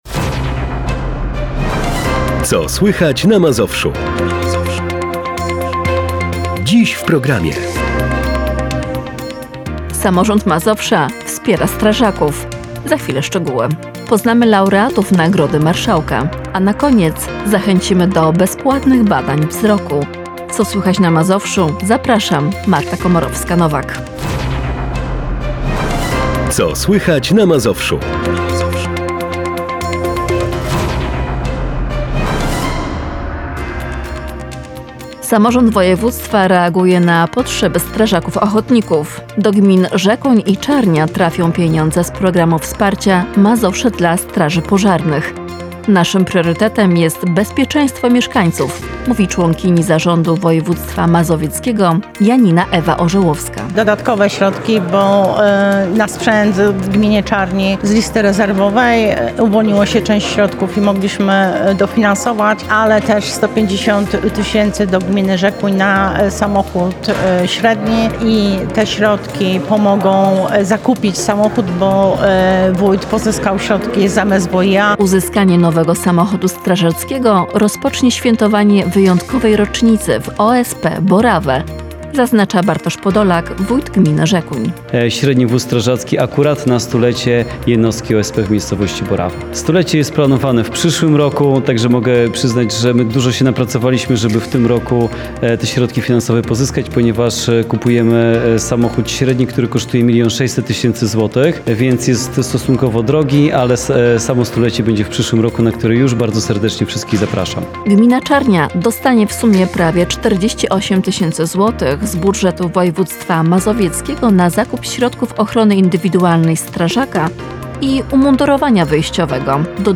Studio plenerowe Radia Bogoria tym razem zawitało do Zabłotni.